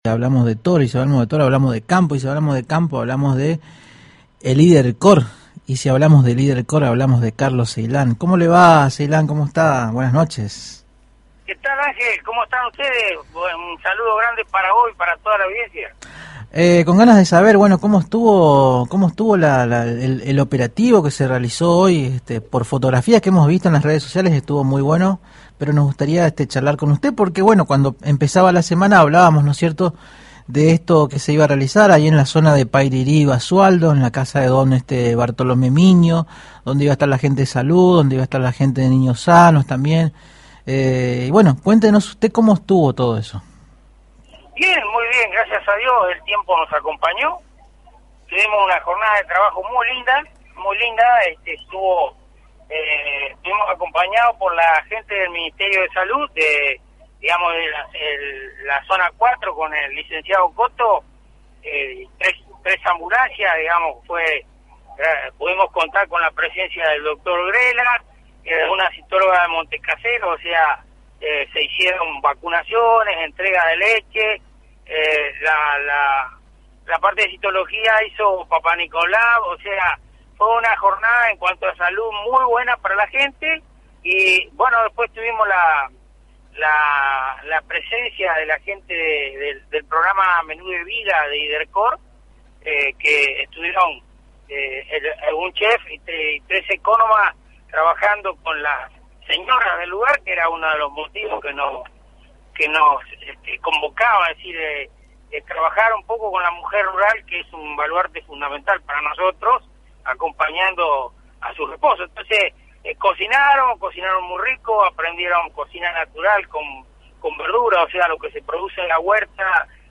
dialogó con Agenda 970